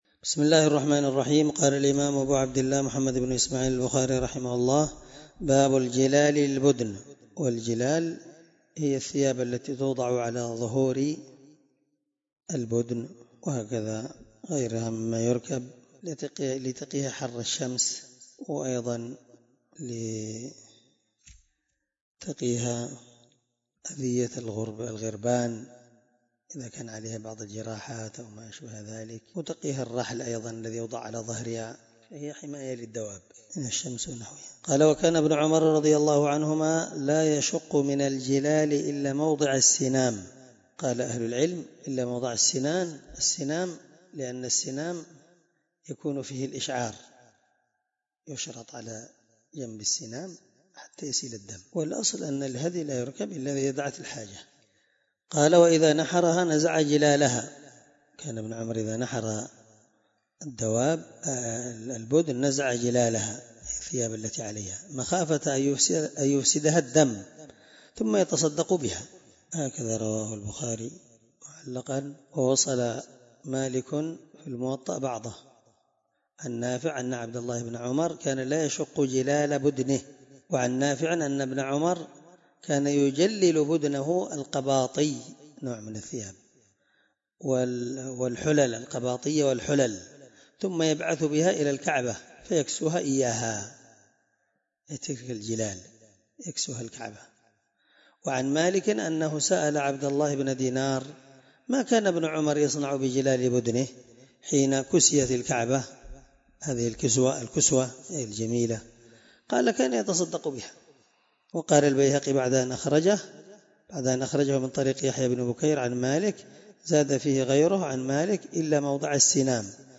الدرس75 من شرح كتاب الحج حديث رقم(1707-1708 )من صحيح البخاري